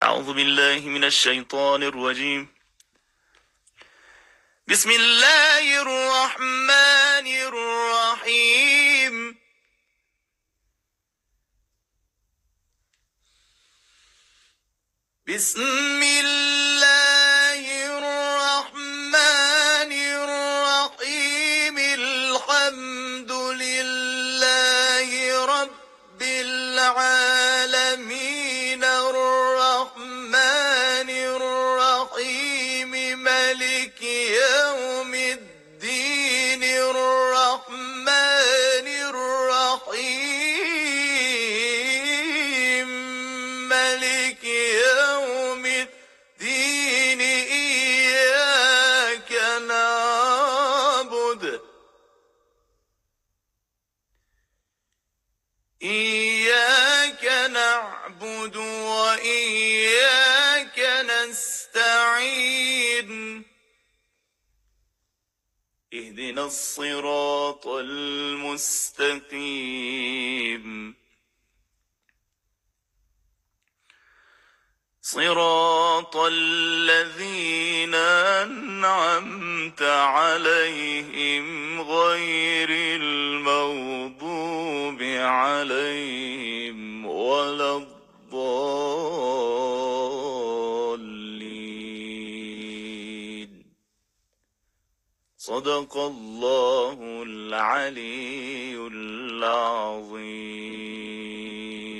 در پایان هدیه معنوی این قاری و‌ مدرس قرآن کریم از سوره حمد تقلیدی از استاد عبدالباسط به مخاطبان تقدیم می‌شود.
قاری قرآن